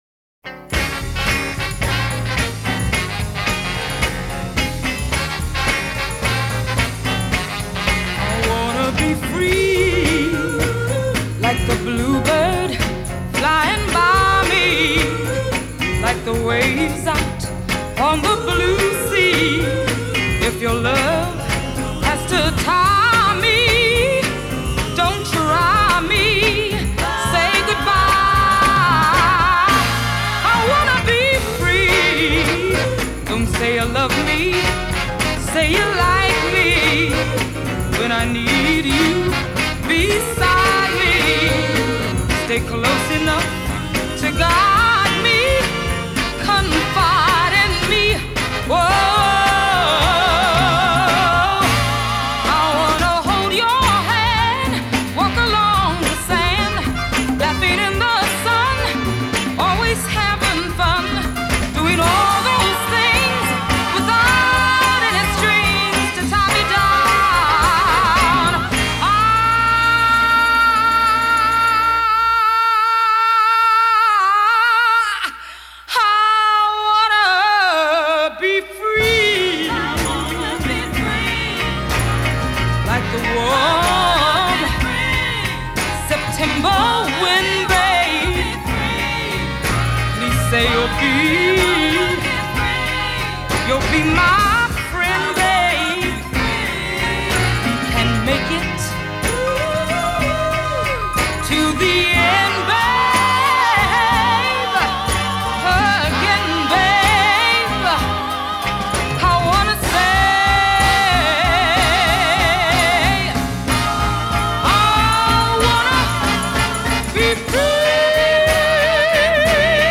Genre: Funk / Soul